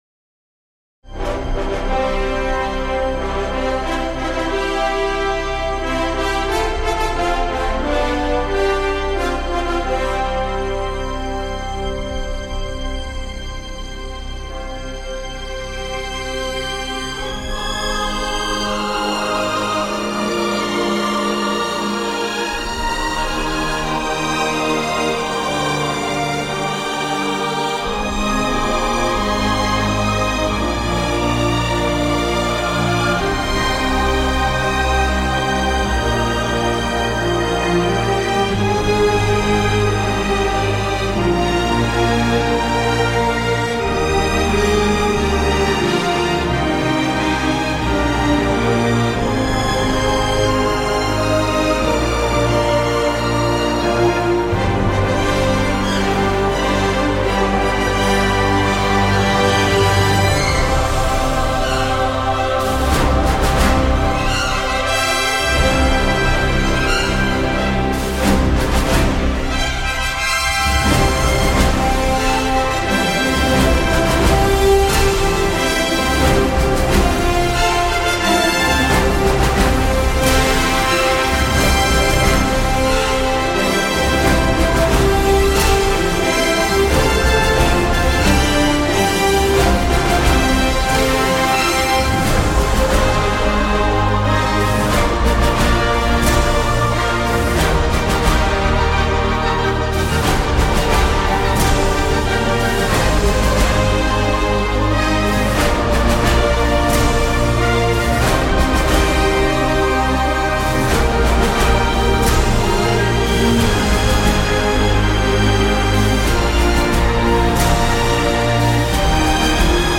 Assourdissant et pompier.